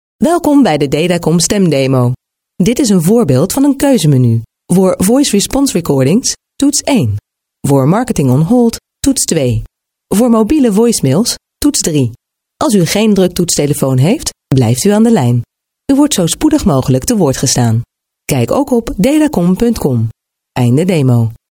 Dutch – female – AK Studio